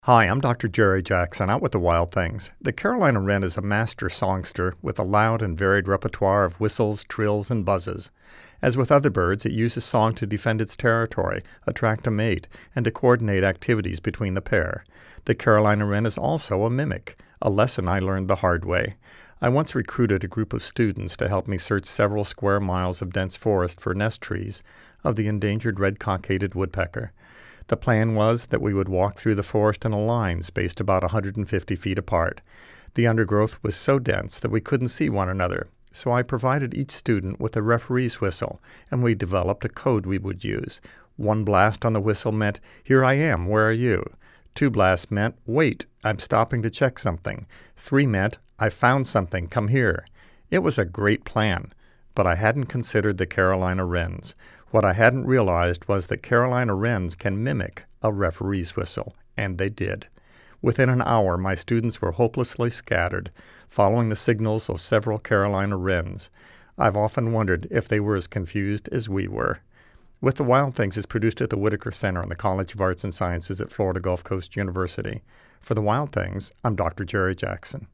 carolina_wren4.mp3